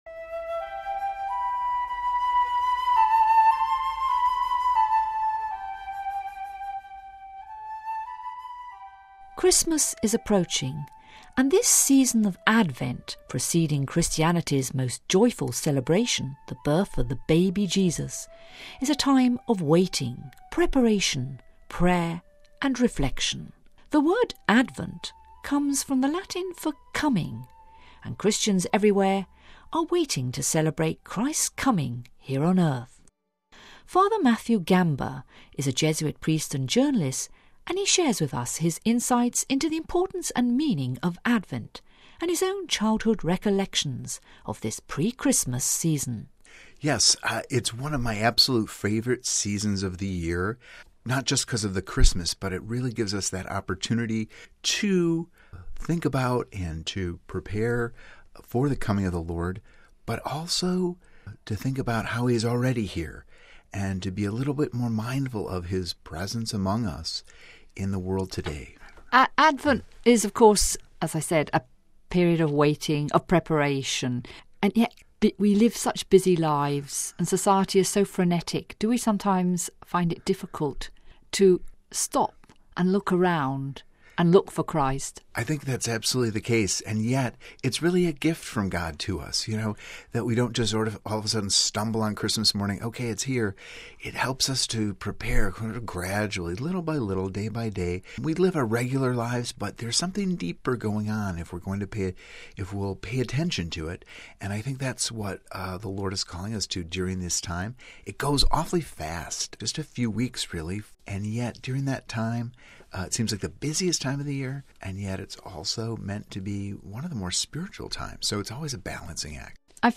ADVENT REFLECTION